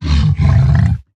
Minecraft Version Minecraft Version snapshot Latest Release | Latest Snapshot snapshot / assets / minecraft / sounds / mob / zoglin / angry1.ogg Compare With Compare With Latest Release | Latest Snapshot
angry1.ogg